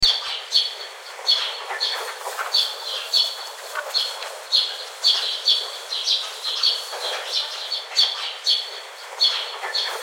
Download Nature sound effect for free.
Nature